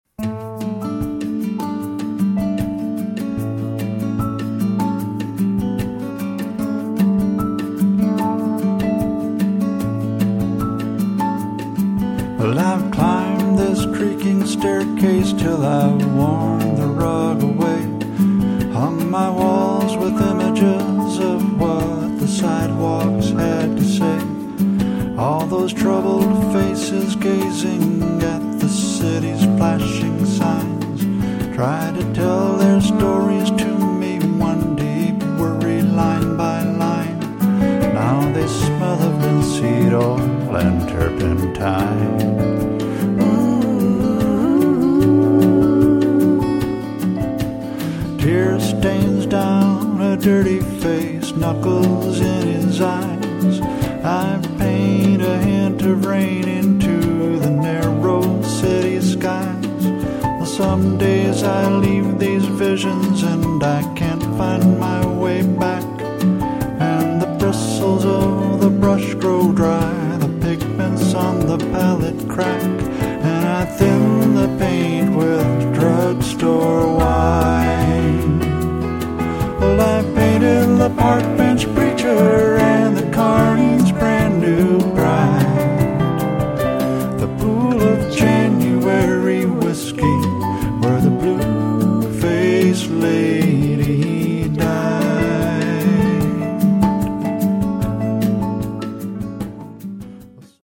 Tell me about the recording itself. Recorded at David Lange Studios